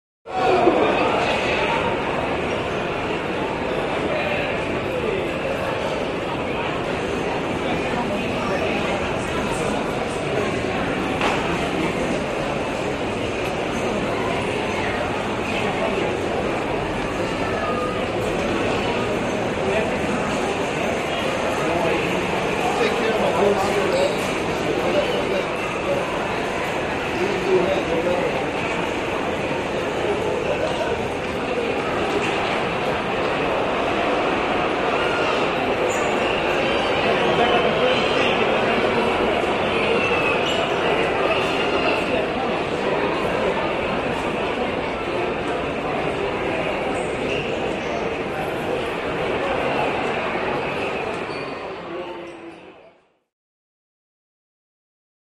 Stadium Crowd Ambience: Concession Stand Point of View; Concession Stand Ambience; Walla / Kids Screaming / Carts Being Rolled, No Real Cheering, Medium Perspective.